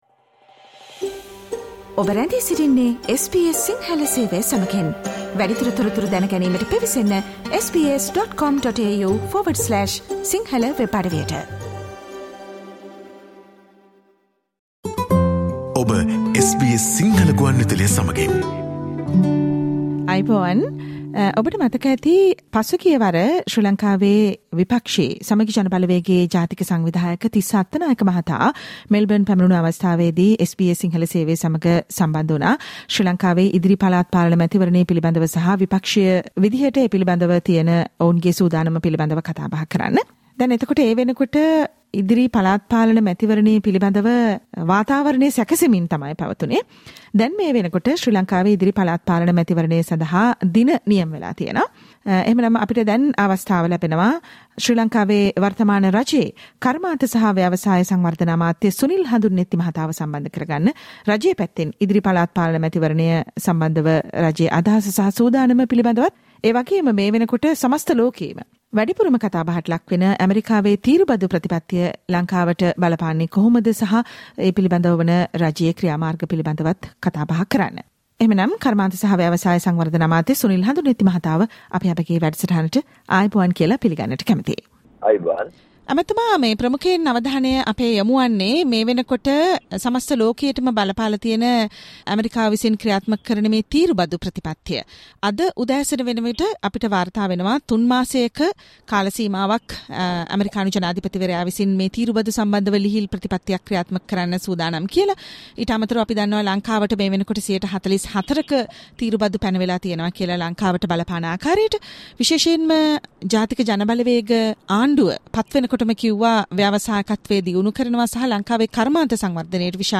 ඇමරිකානු තීරුබදු සහ පළාත්පාලන මැතිවරණය ගැන ඇමති සුනිල් හඳුන්නෙත්ති SBS සිංහල සේවය හමුවේ කතා කරයි
ඇමරිකානු තීරුබදු ශ්‍රී ලංකාවට බලපාන ආකාරය සහ පළාත්පාලන මැතිවරණය පිළිබඳව ශ්‍රී ලංකාවේ කර්මාන්ත සහ ව්‍යවසාය සංවර්ධන අමාත්‍ය සුනිල් හඳුන්නෙත්ති මහතා SBS සිංහල සේවය හමුවේ පැවසු තොරතුරු ඇතුලත් සාකච්චාව